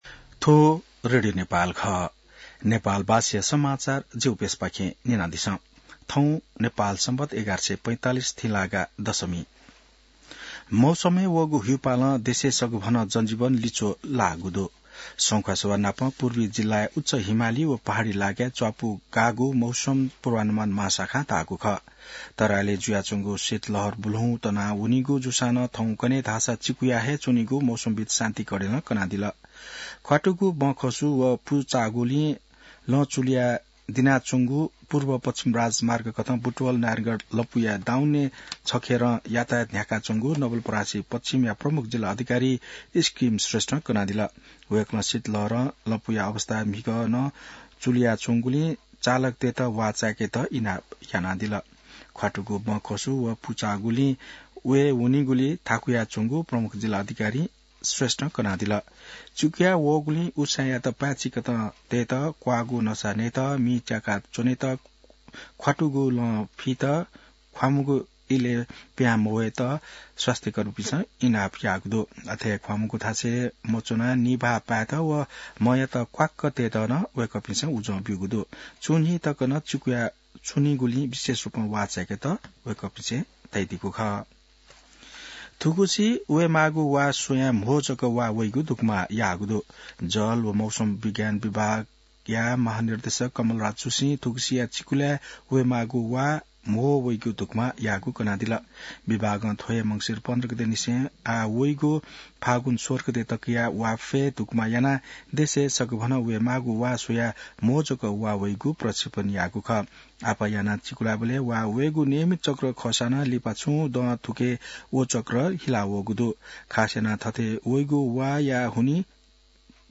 नेपाल भाषामा समाचार : २६ पुष , २०८१